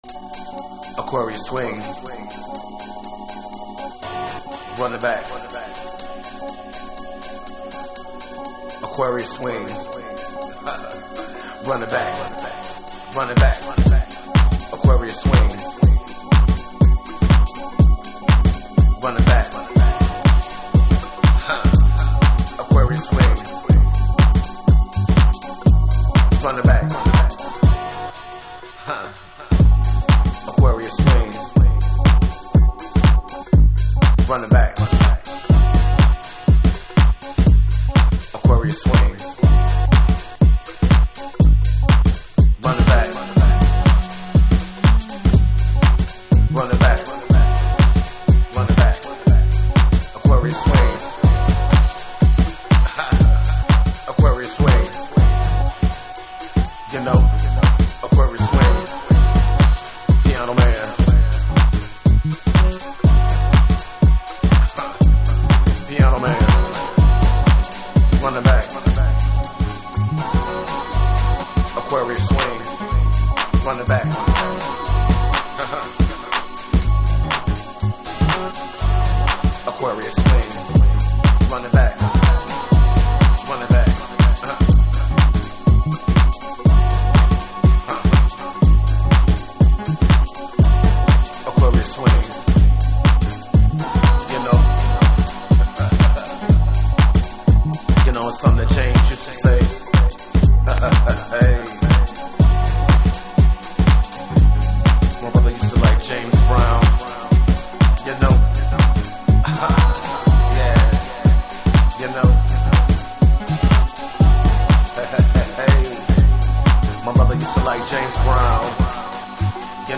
Detroit House / Techno
90'sマナーのディープとガラージの折衷感覚とゼロ年代以降のデトロイトのスモーキーな音像を併せ持ったTRAX。